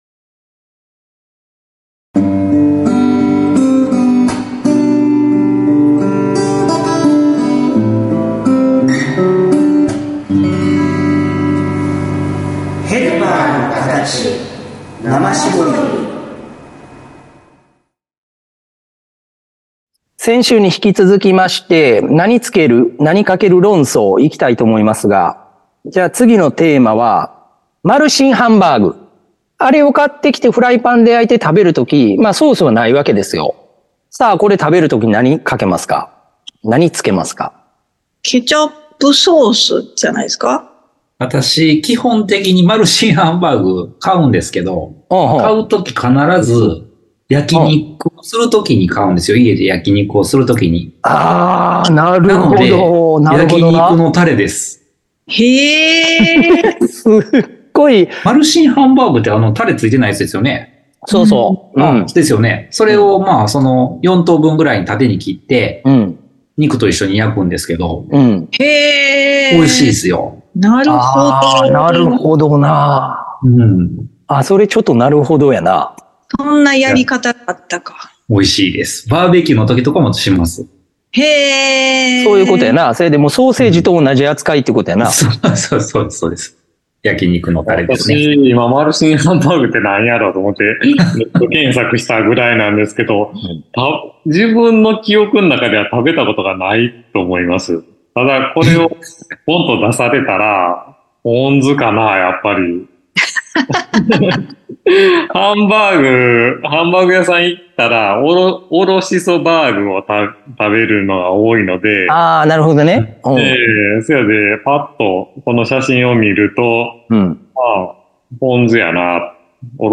＜今週のテーマ＞ 先週に引き続きまして 「この料理には何かける？」 をテーマにした談論です。